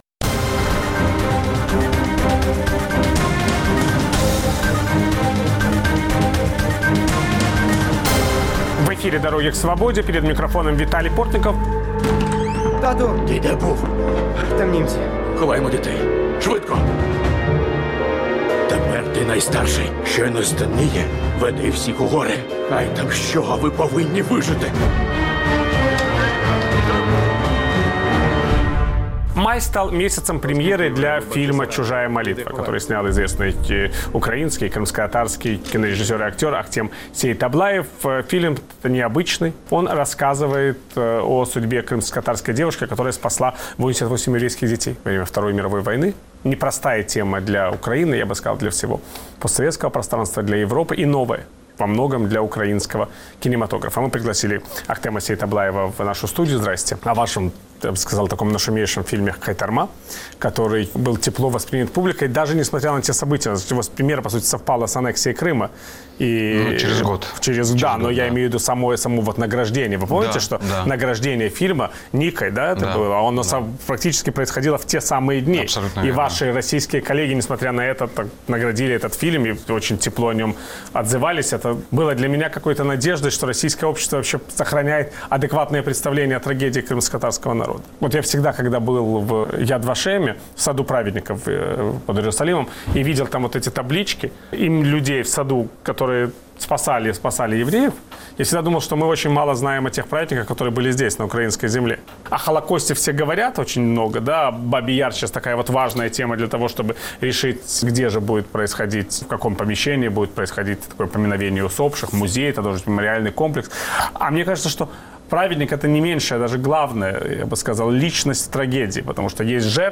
Украинский и крымскотатарский режиссер Ахтем Сеитаблаев представил зрителям свой новый фильм "Чужая молитва", посвящённый подвигу крымскотатарской женщины, которая спасла в годы войны десятки еврейских детей. Виталий Портников беседует с Ахтемом Сеитаблаевым об истории создания фильма и его героях